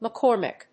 /mʌˈkɔrmɪk(米国英語), mʌˈkɔ:rmɪk(英国英語)/